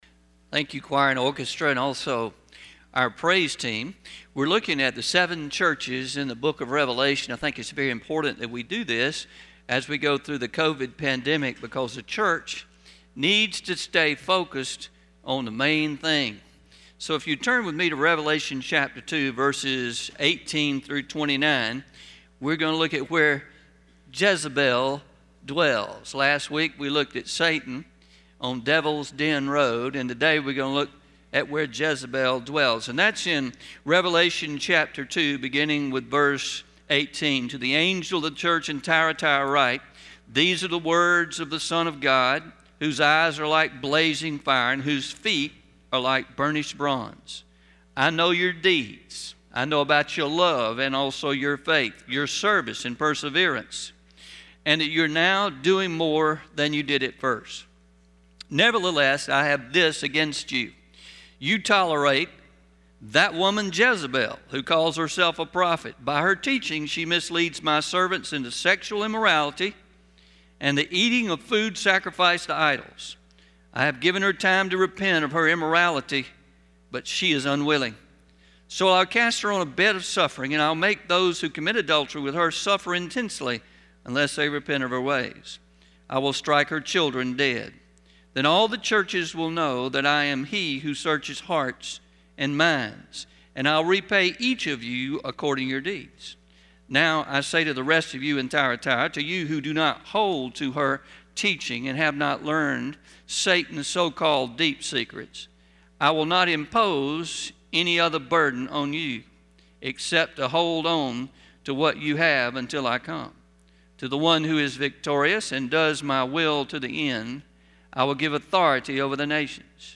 Traditional 09-19-21am Sermon – Where Jezebel Dwells